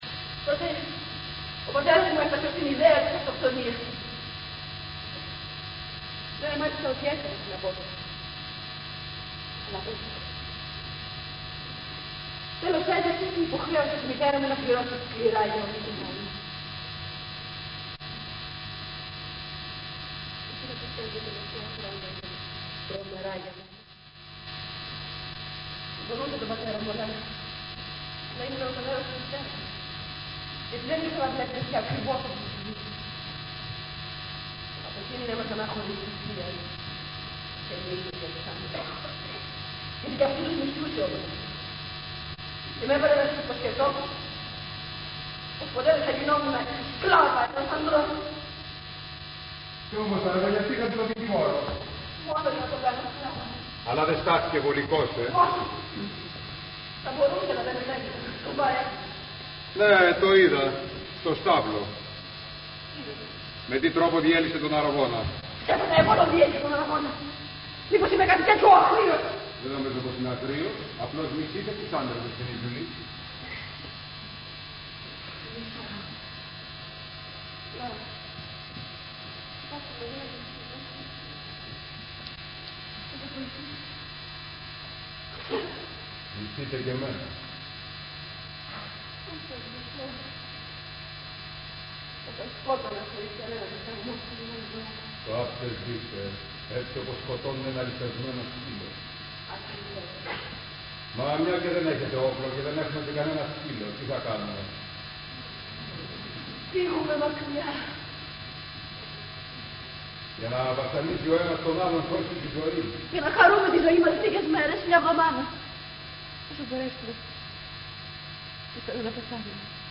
Ηχογράφηση Παράστασης
. Δεσποινίς Τζούλια/ Η πιο δυνατή / Στρίντμπεργκ Αύγουστος (Συγγραφέας) / Κατσέλης Πέλος (Σκηνοθεσία) - Εταιρεία Μακεδονικών Σπουδών - Κεντρική Σκηνή (19/11/1975)